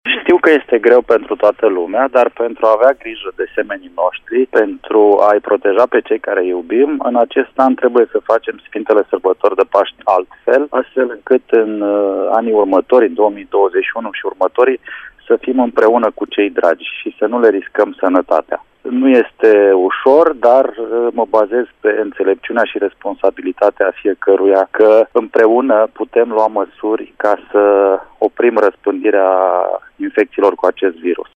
Împreunã putem lua mãsuri pentru a stopa infecţia cu acest nou coronavirus a subliniat ministrul Marcel Vela: